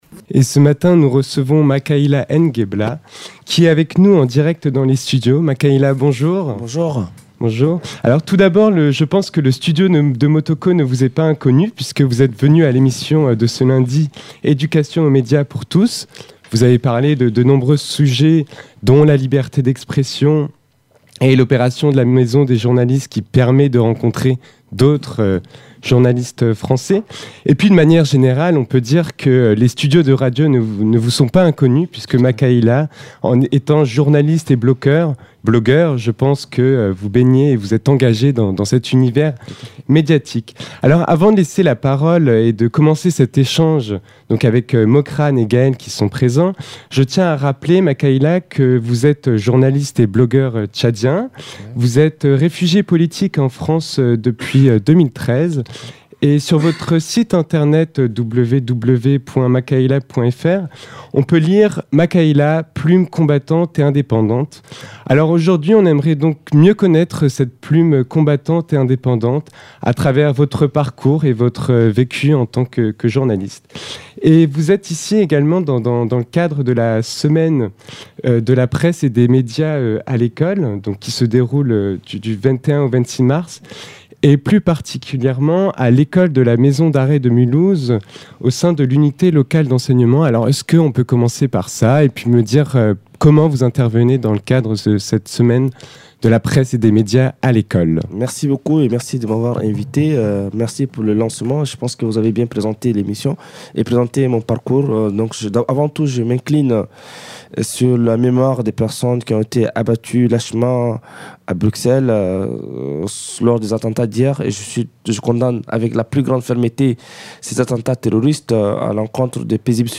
Emission Frühstück, la matinale de Radio MNE du 23 mars 2016 avec l’interview